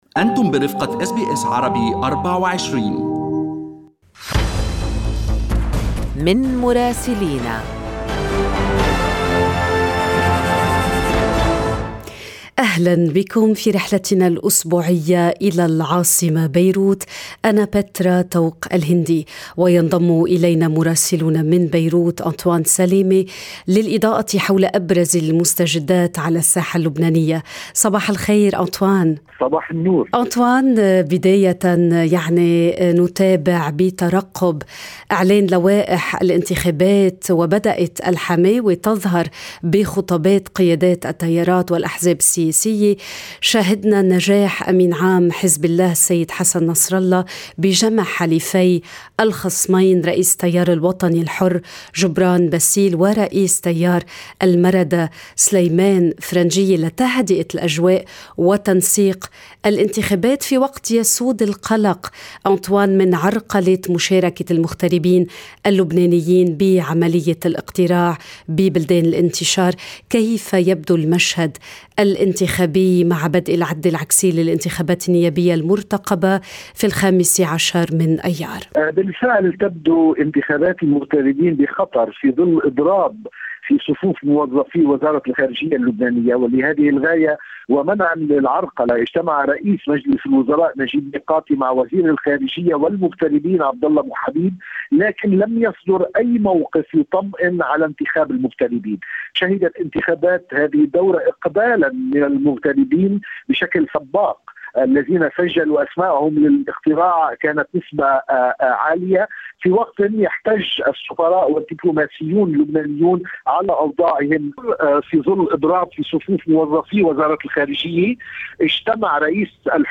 من مراسلينا: أخبار لبنان في أسبوع 12/4/2022